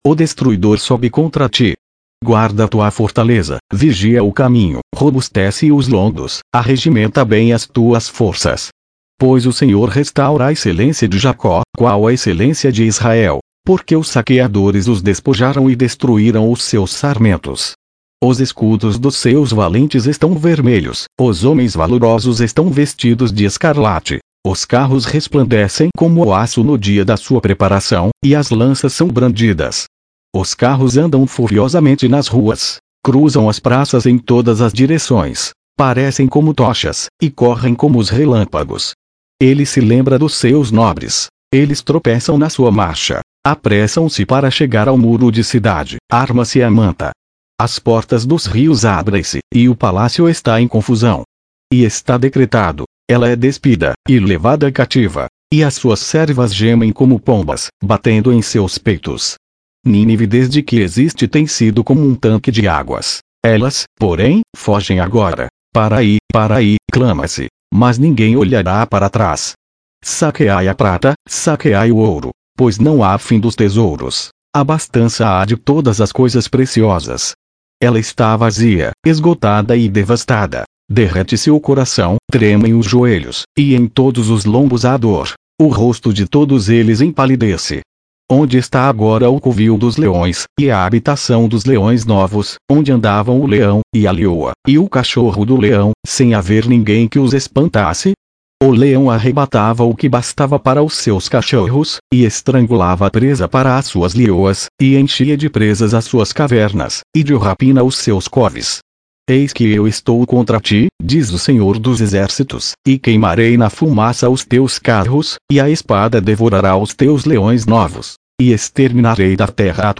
Leitura na versão Revisada - Portugués